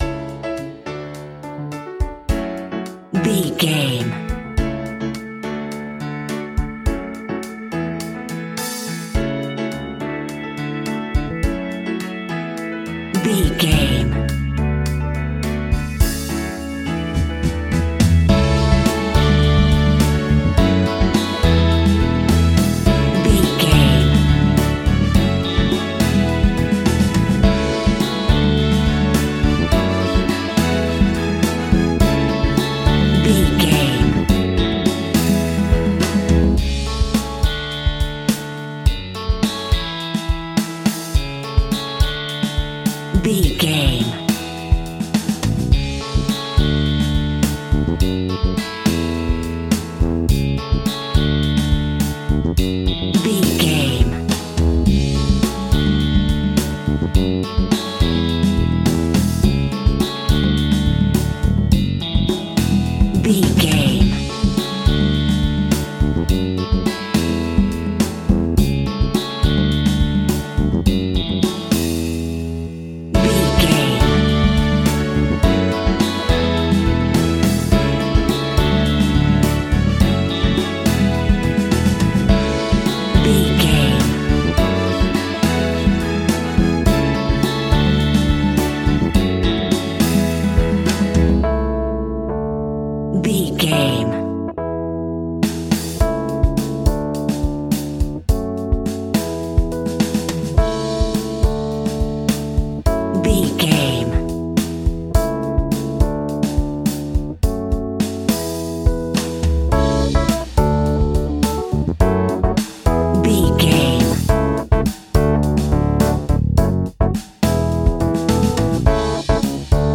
Ionian/Major
cheesy
electro pop
pop rock
happy
upbeat
bouncy
drums
bass guitar
electric guitar
keyboards
hammond organ
acoustic guitar
percussion